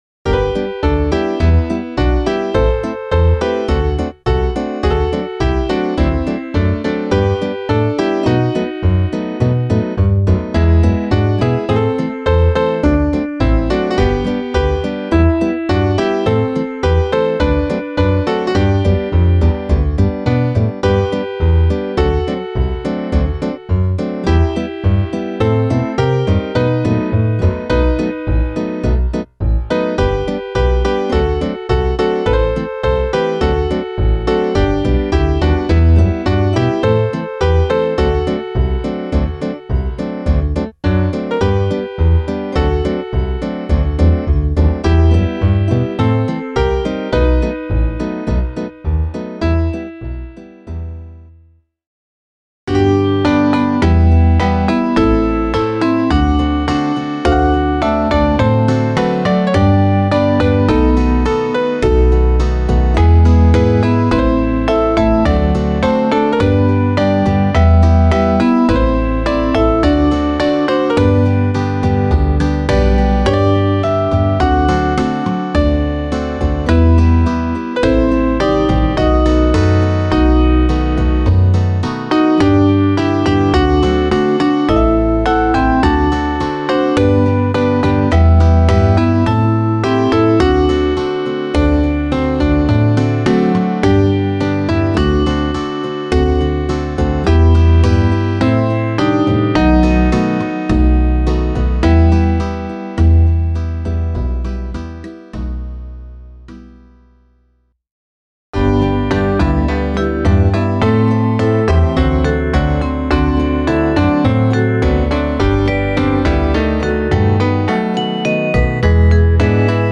Klaviersatz
Variationen